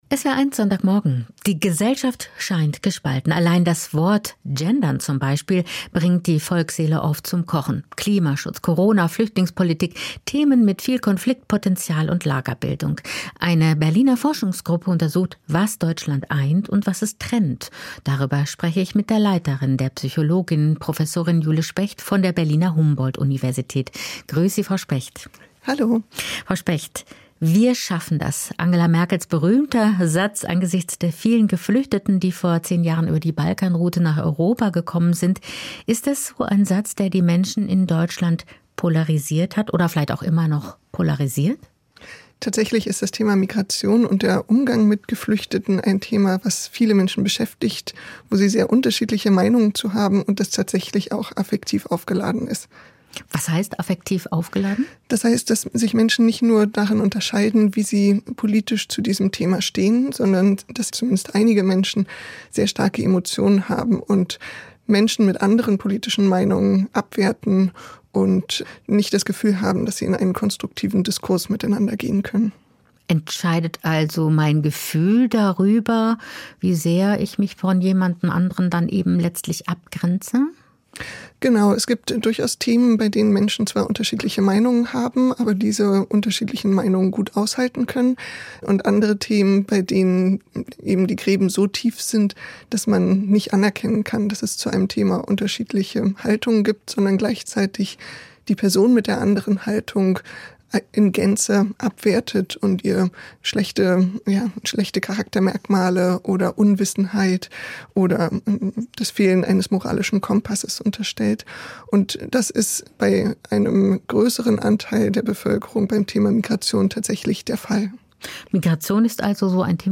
Wie polarisiert ist unsere Gesellschaft? Gespräch